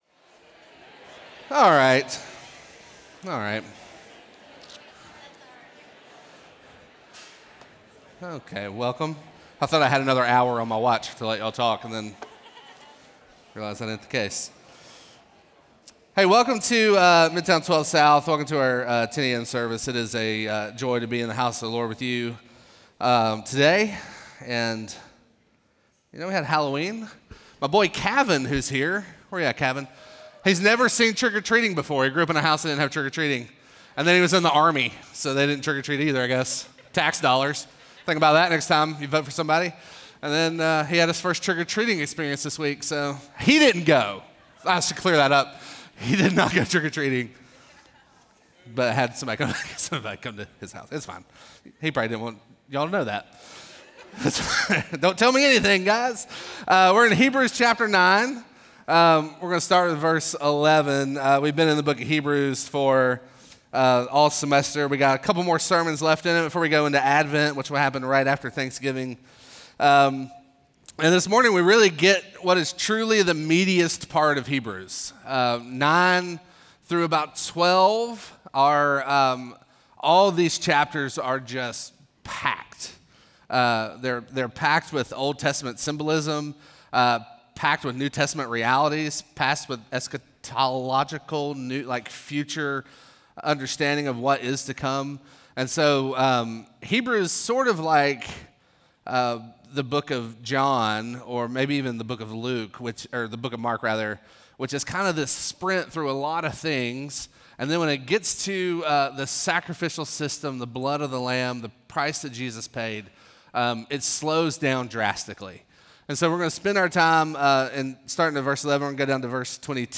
Midtown Fellowship 12 South Sermons